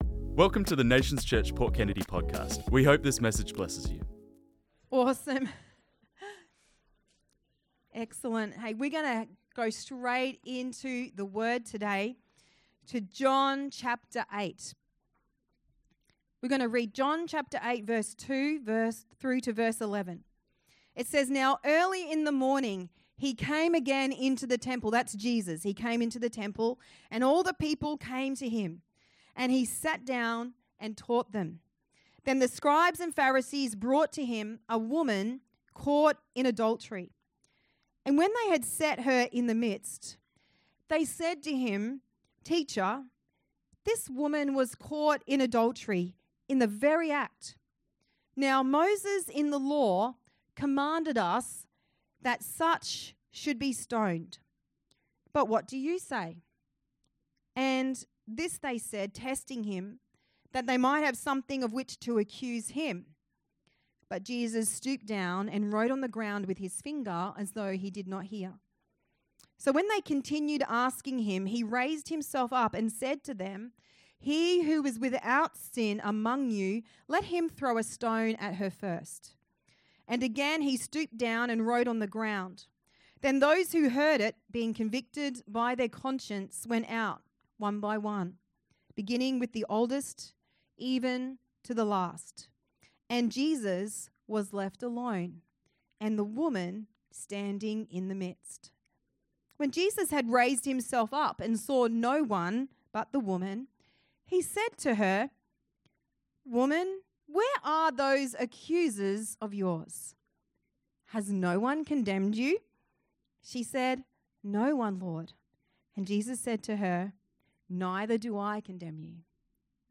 This message was preached on Sunday 30th March 2025